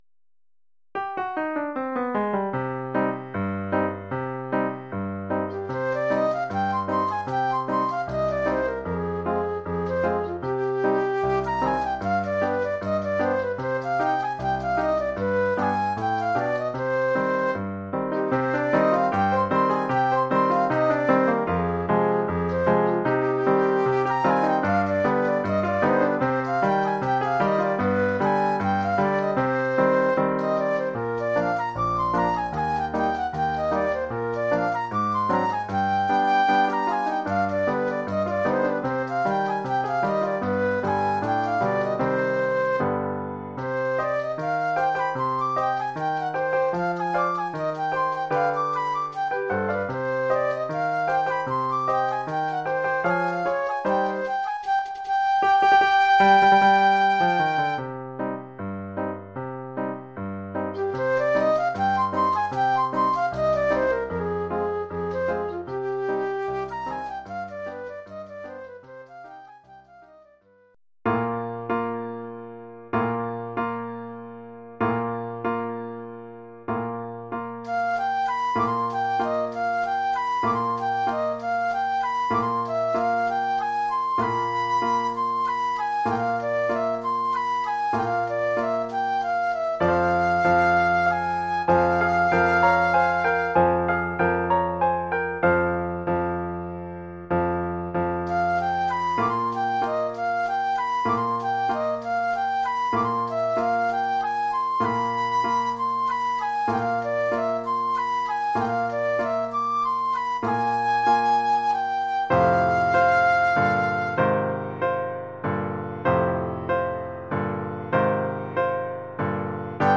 Oeuvre pour flûte et piano.